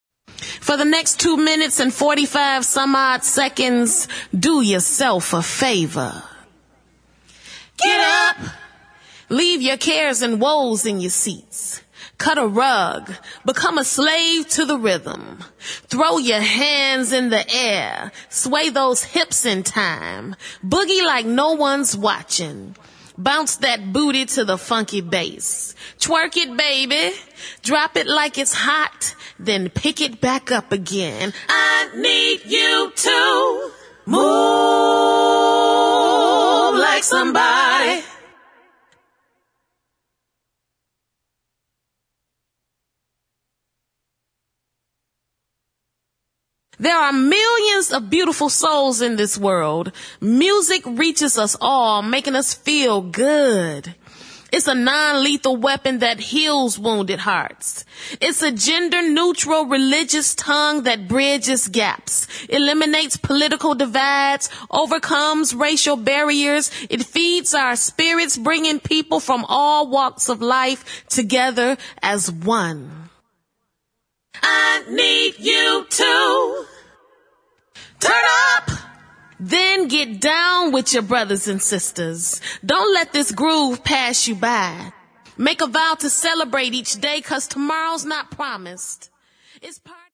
[ DEEP HOUSE | DISCO ]
(Accapella)